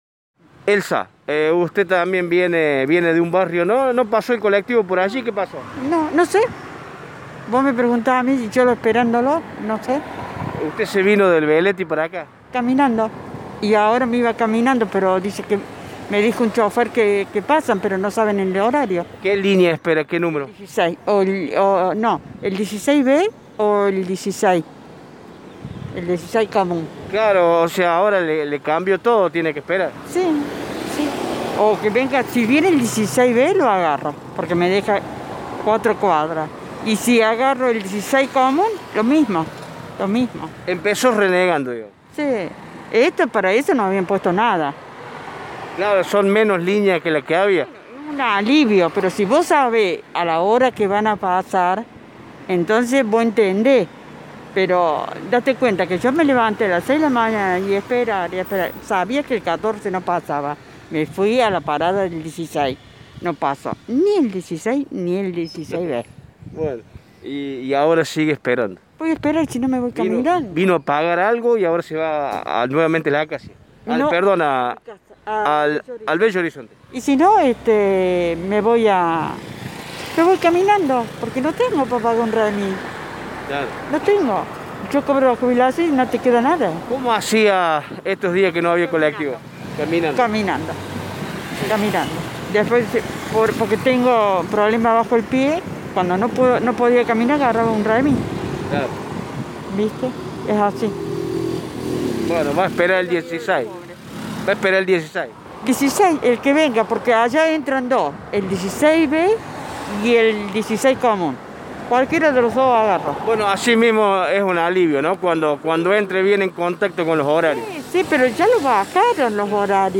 Dos pasajeras y un chofer hablaron con Radio Show.